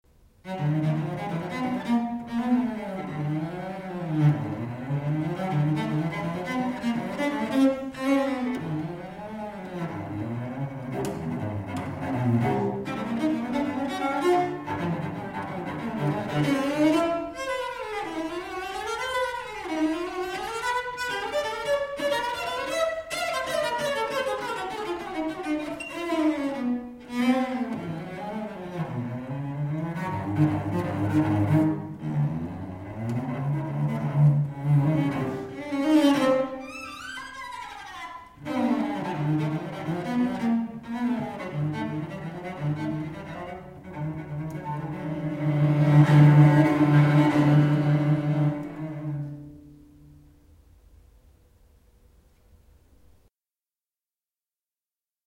wiolonczela